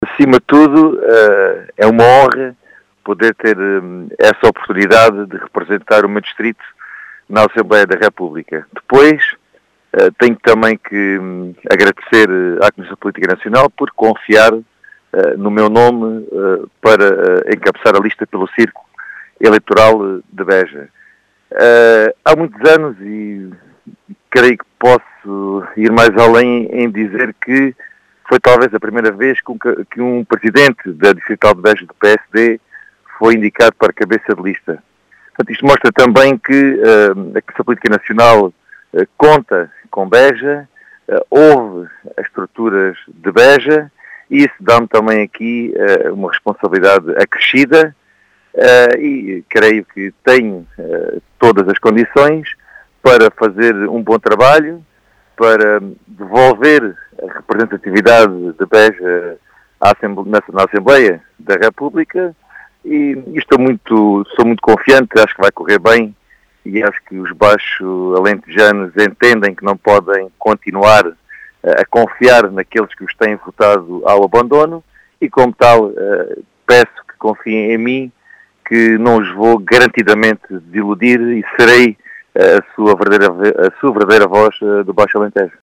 Em declarações à Rádio Vidigueira